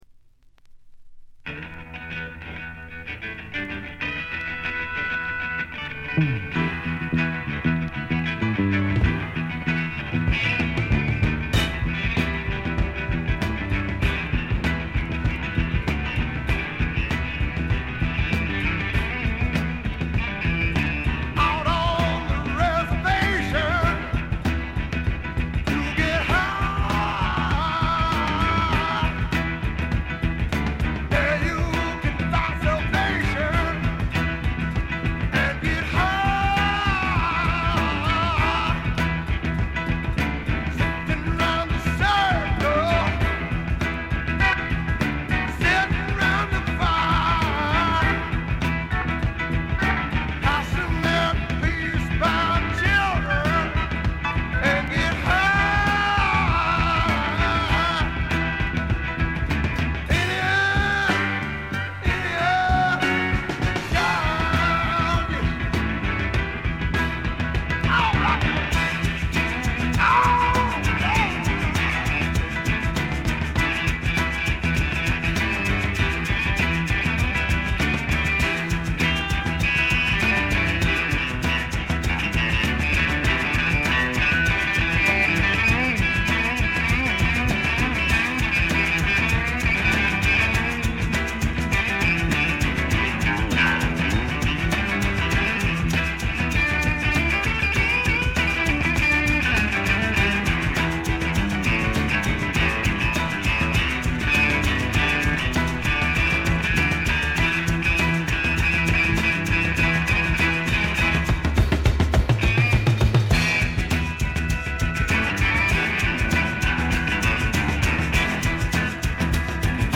ごくわずかなノイズ感のみ。
サイケ＆スワンプの傑作！
試聴曲は現品からの取り込み音源です。